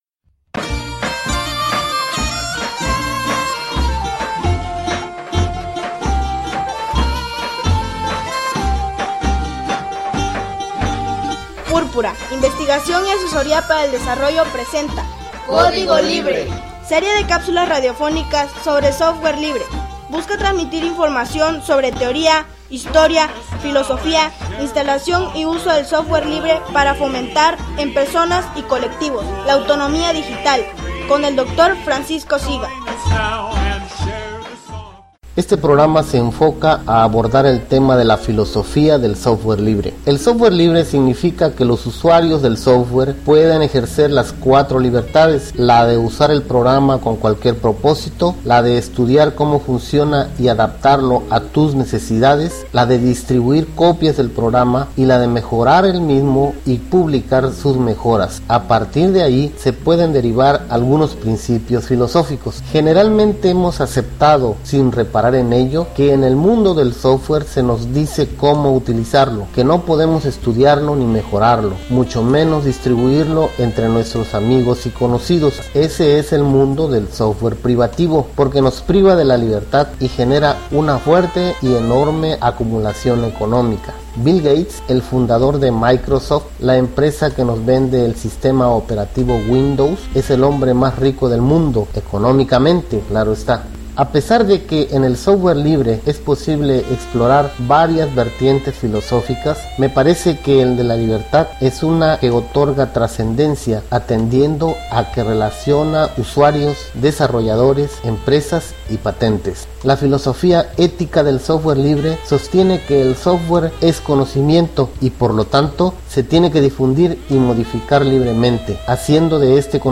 Serie de capsulas radiofónicas sobre Software Libre.